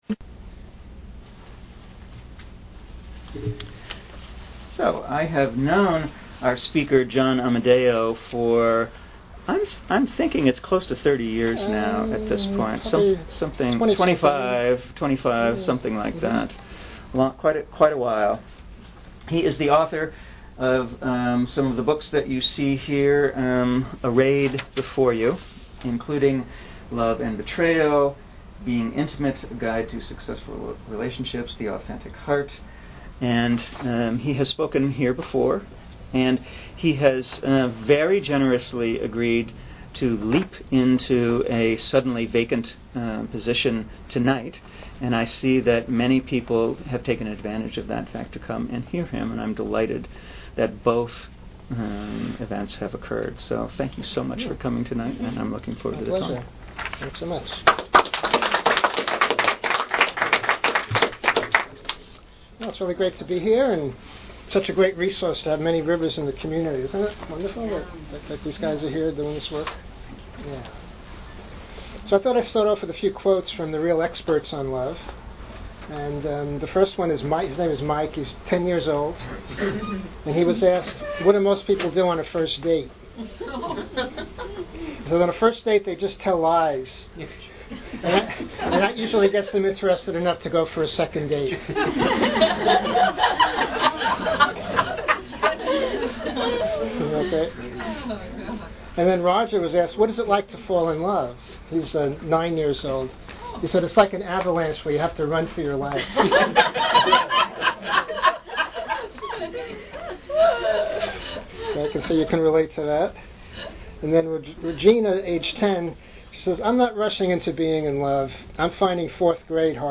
Archive of an event at Sonoma County's largest spiritual bookstore and premium loose leaf tea shop.
Through discussion, a scripted role play, and Focusing, we'll see how the key to deeper connections is to bring mindfulness to our felt experience.